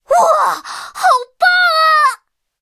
M4A3E2小飞象获得资源语音.OGG